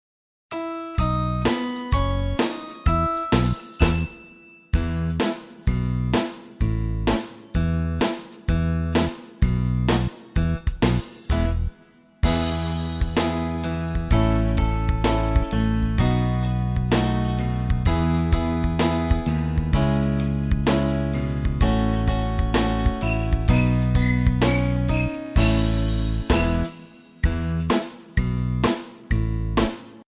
Listen to a sample of the instrumental track.